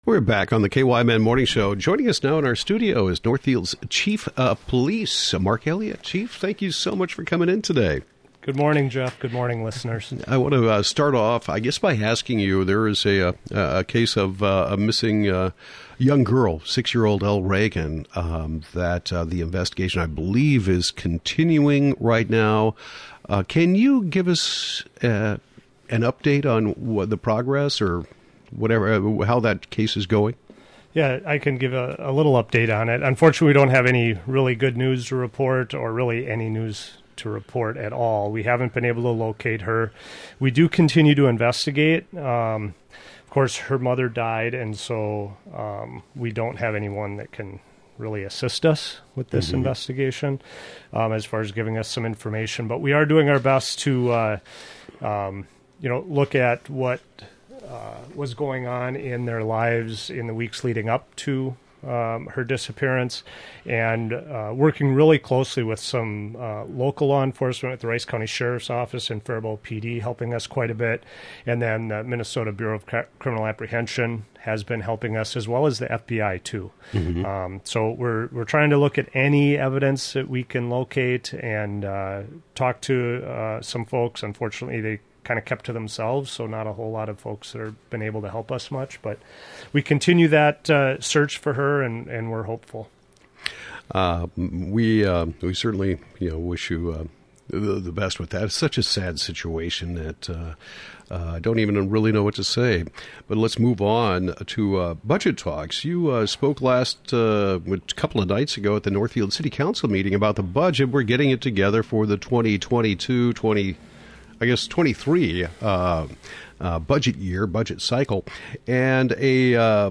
Police Chief Mark Elliott discusses request for new position, mental health services, and more
Northfield Police Chief Mark Elliott talks about a request to add an administrative sergeant position as the City Council considers its 2022-23 budget, discusses mental health resources, and more.